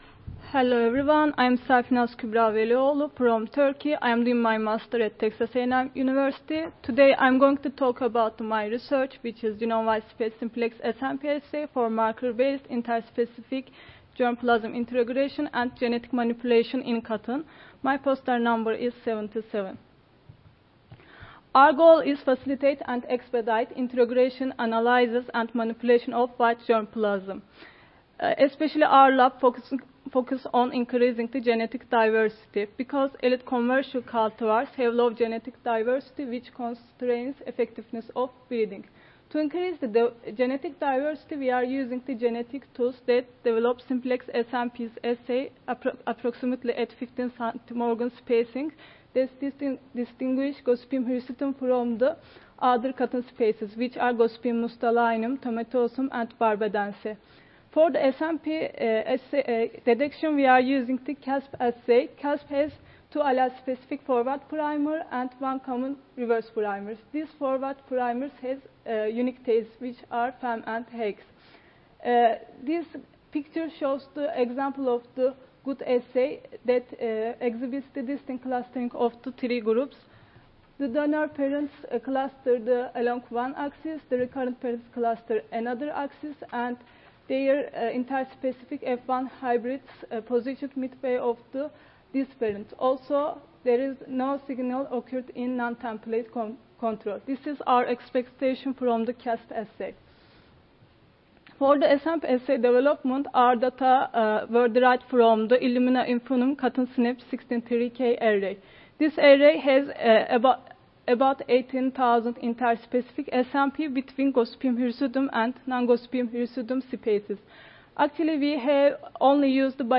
Cotton Improvement - Lightning Talks Student Competition
Audio File Recorded Presentation